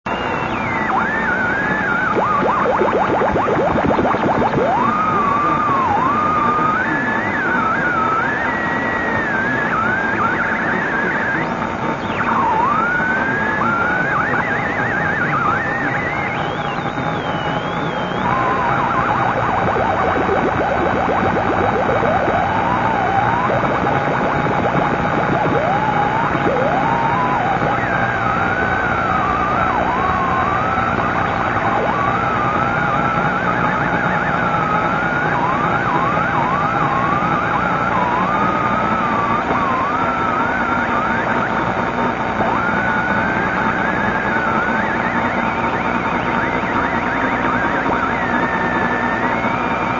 Plate to Plate - one transistor theremin
Now approach your hand slowly to the metal plate - the whistle's tune will change. Try moving slow, fast, shaking your hand - with some practice it's possible to get interesting sounds.
some not so interesting sounds...